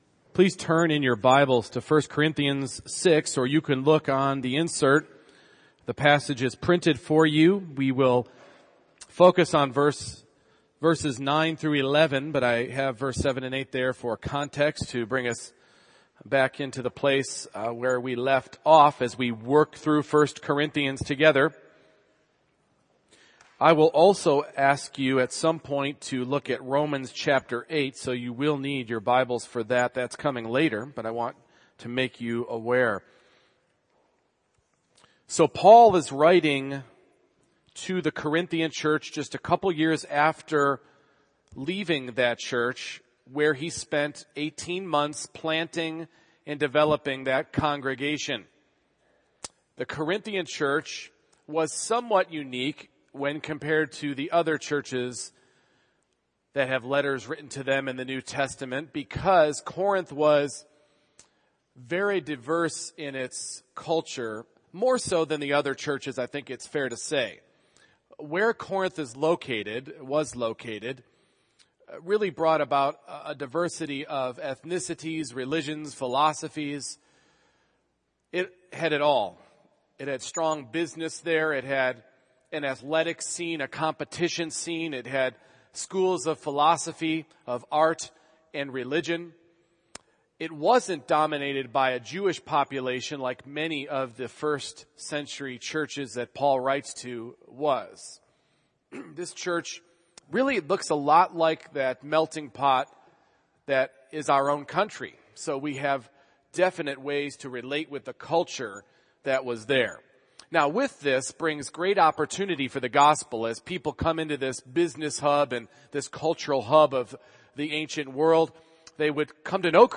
1 Corinthians 6:9-11 Service Type: Morning Worship A Christian’s primary identity is Christ.